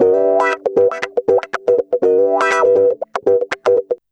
Index of /90_sSampleCDs/USB Soundscan vol.04 - Electric & Acoustic Guitar Loops [AKAI] 1CD/Partition C/04-120GROWAH